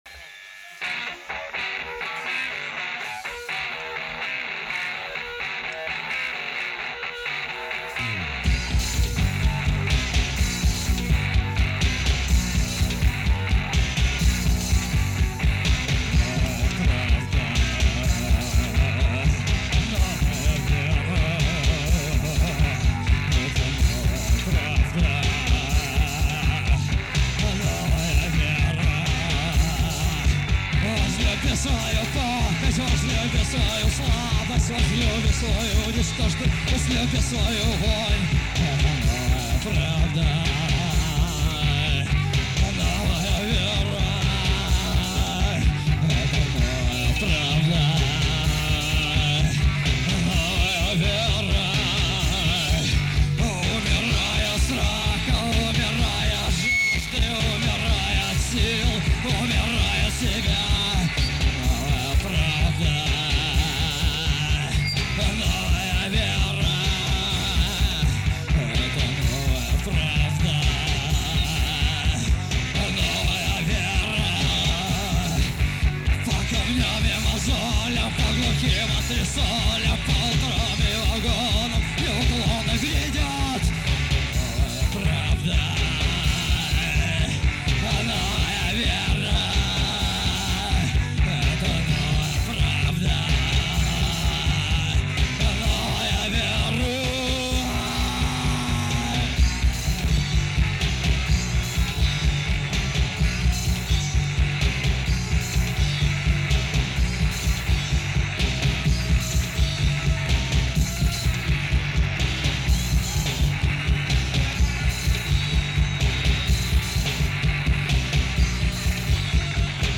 советская поэтесса, рок-певица и автор-исполнитель.
(Live)